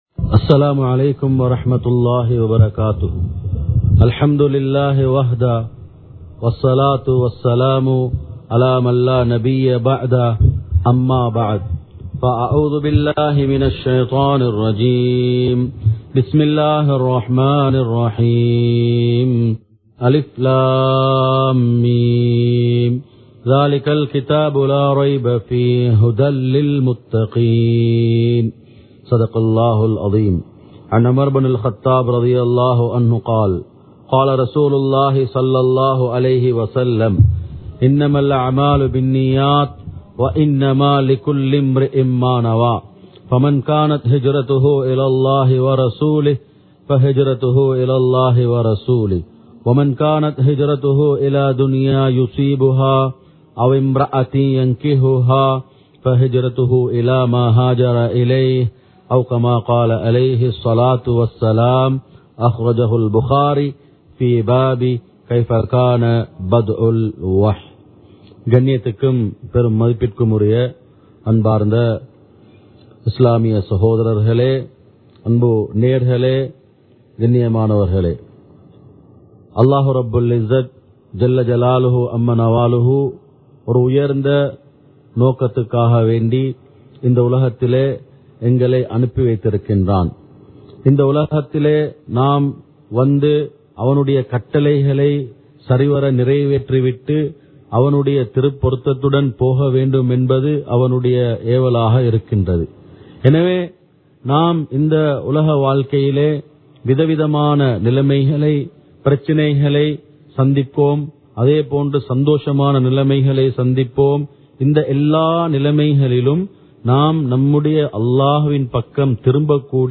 அல்லாஹ் போதுமானவன் (Be the Satisfiction on allah) | Audio Bayans | All Ceylon Muslim Youth Community | Addalaichenai
Kollupitty Jumua Masjith